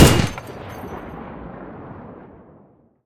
gun-turret-end-3.ogg